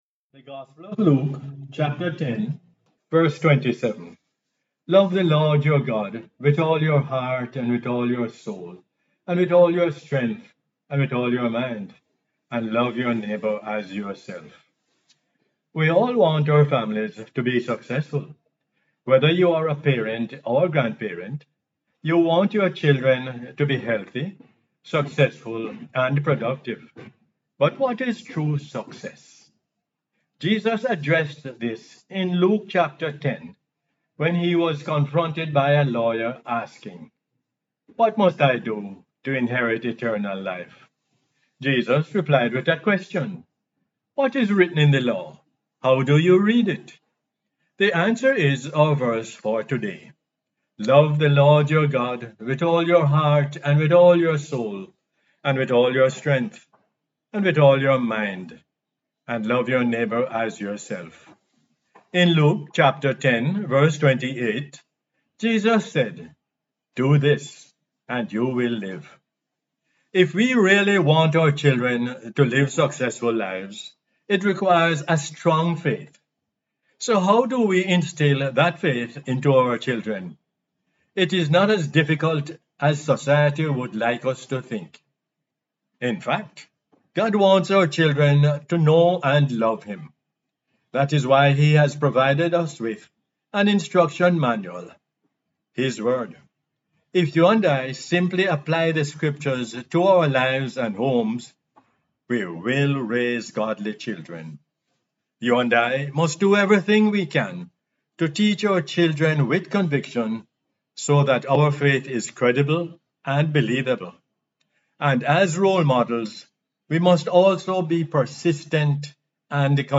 Luke 10:27 is the "Word For Jamaica" as aired on the radio on 13 May 2022.